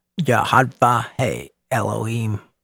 yud-heh-vahv-heh el-oh-heem
yud-heh-vahv-heh-elo-oh-hem.mp3